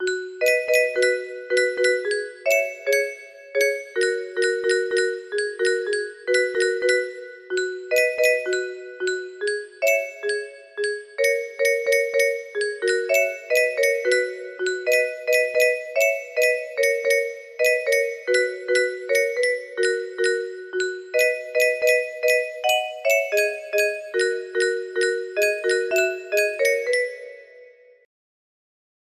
I See The Moon music box melody